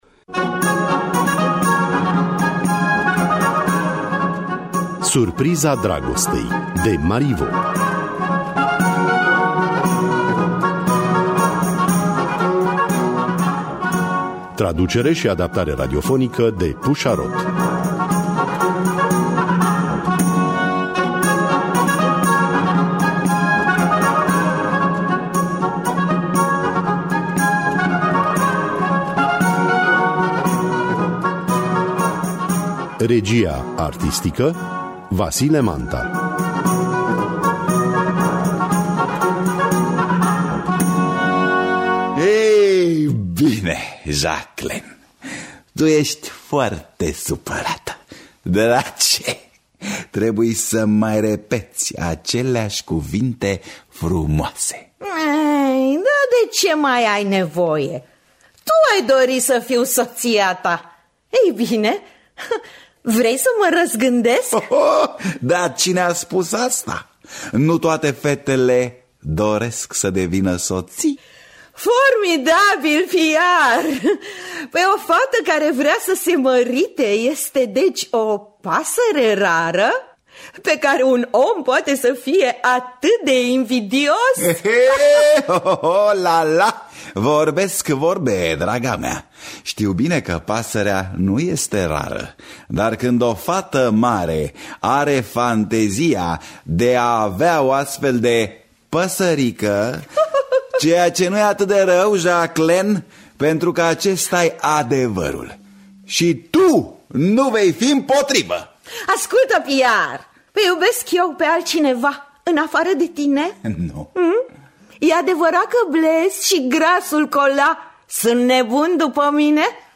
Pierre Carlet de Chamblain de Marivaux – Surpriza Dragostei (2018) – Teatru Radiofonic Online